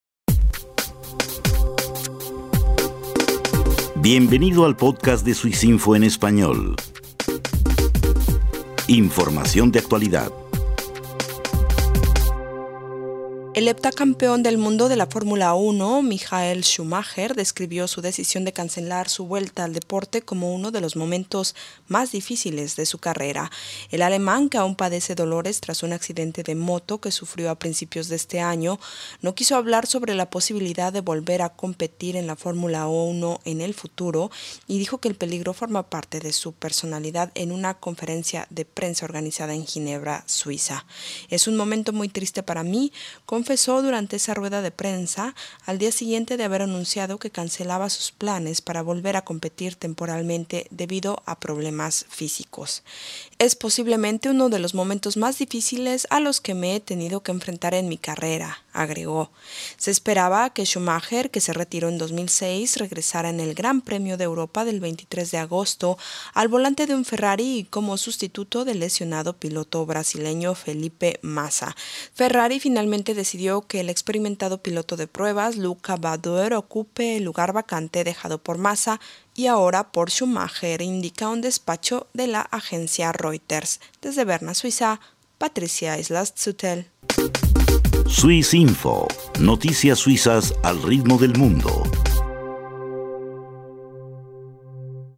Lamentó el anuncio en rueda de prensa en Ginebra.